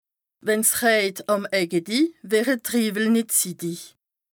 Bas Rhin
Ville Prononciation 67